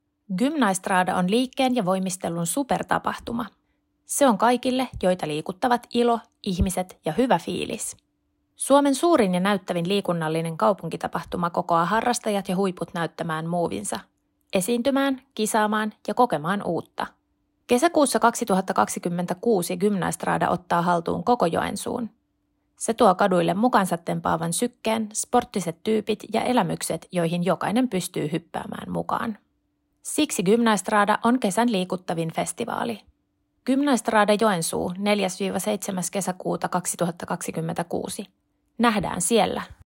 Gymnaestrada-äänimainos - Materiaalipankki